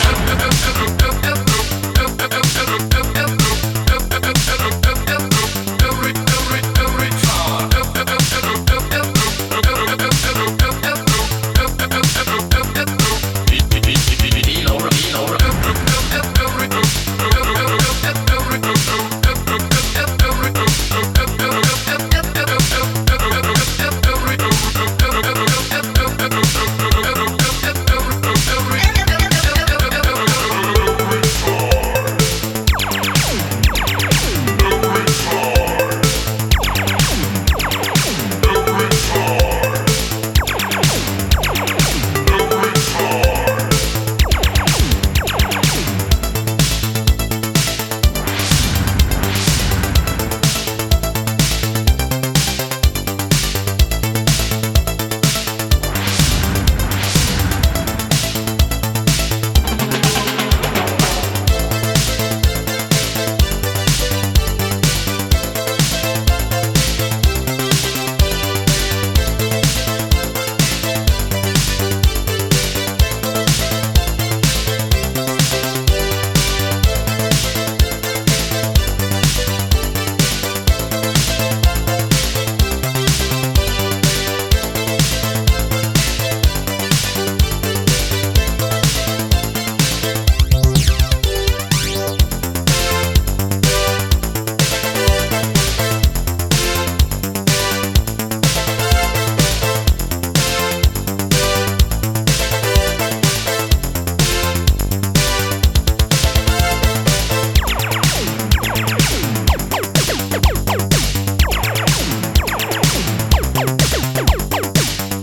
2010年にリリースされていたとは俄には信じ難い、まんま1984年か1985年の音。
Instrumental